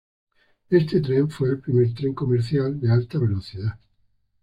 Read more (masculine) train (masculine, rare) extravagance Frequency A2 Pronounced as (IPA) /ˈtɾen/ Etymology Borrowed from French train In summary Borrowed from French train.